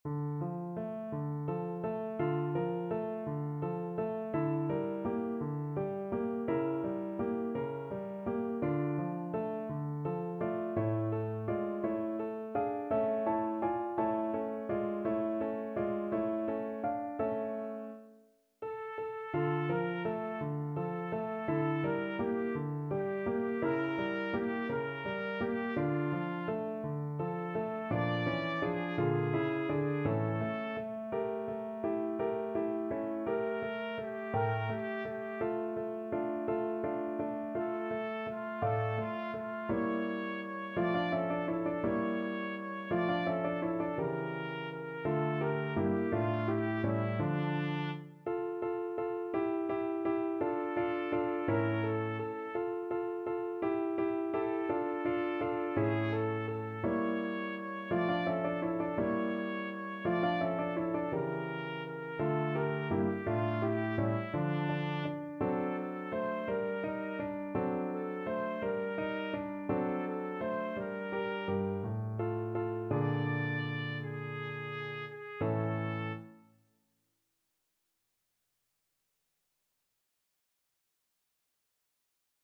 6/8 (View more 6/8 Music)
D5-D6
. = 56 Andante
Classical (View more Classical Trumpet Music)